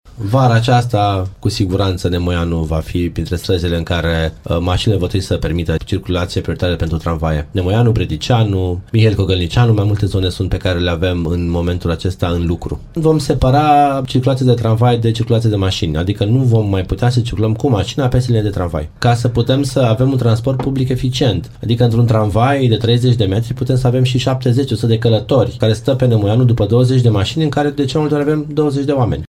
Viceprimarul Ruben Lațcău spune că în felul acesta tramvaiele nu vor mai pierde timp în trafic.